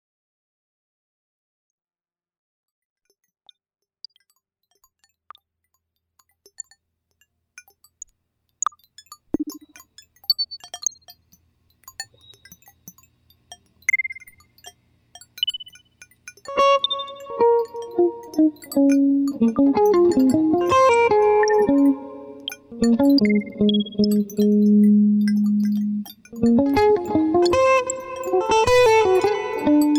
Adventurous Electronic Excursions
Voice with Live Processing
Guitar and SuperCollider
A free improv interpretation
with an abundance of live sound processing.